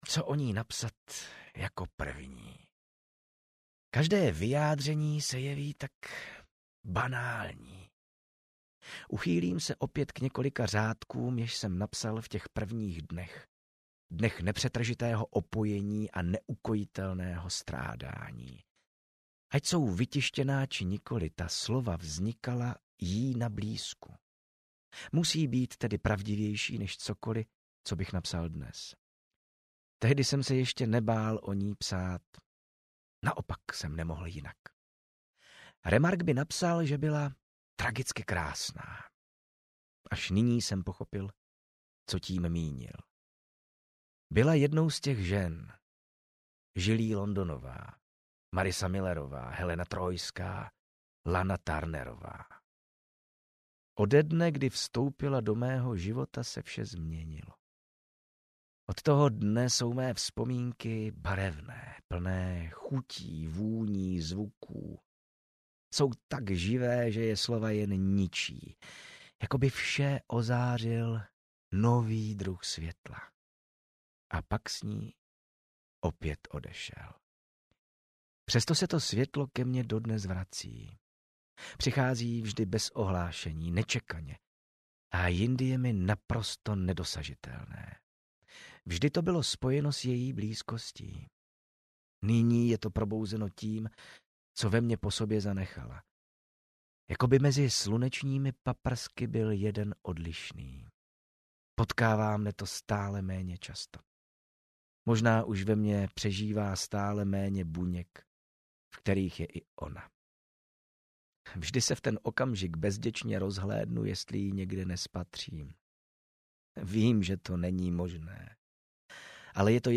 Úterní poledne audiokniha
Ukázka z knihy